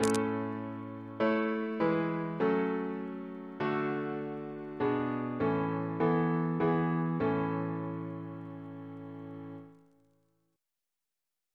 Single chant in A Composer: Stephen Elvey (1805-1860), Organist of New College, Oxford; George's brother Reference psalters: ACB: 121; ACP: 253; H1940: 615 663; H1982: S206; OCB: 124; PP/SNCB: 70